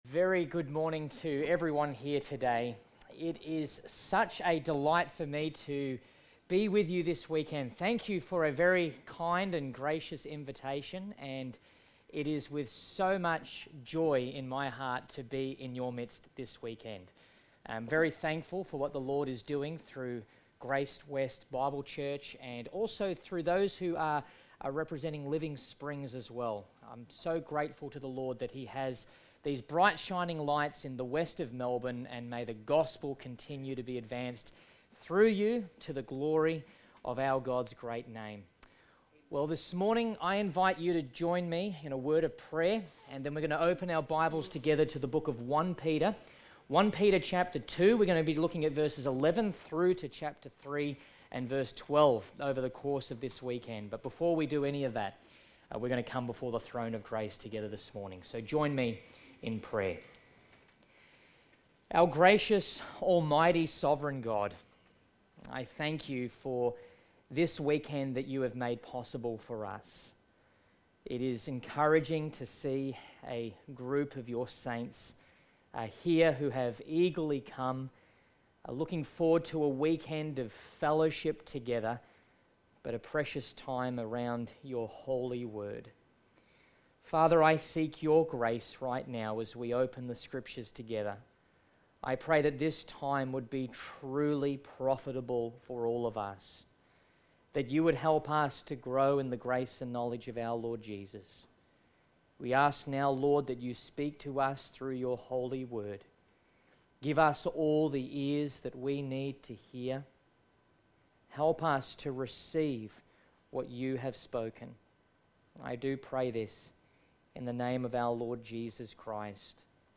Series: GraceWest Camp 2023 Everyday Faithfulness
Service Type: Special Event